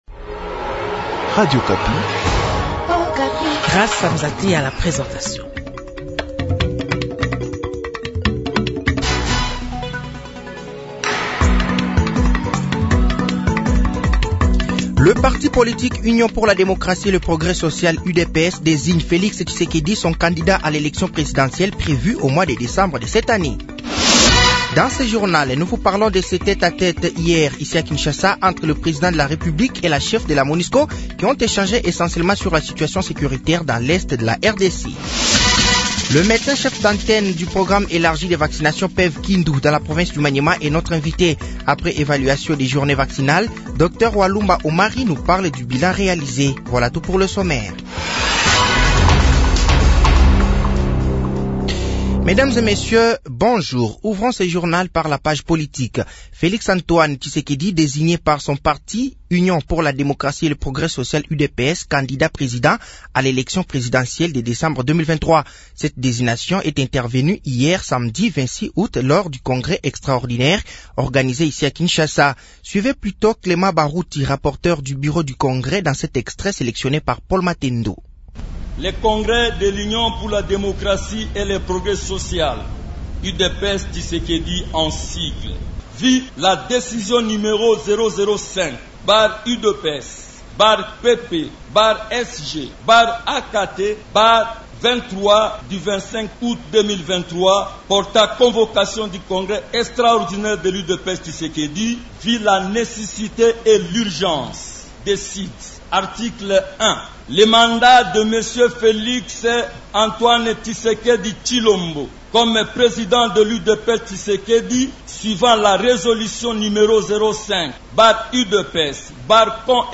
Journal français de 12h de ce dimanche 27 août 2023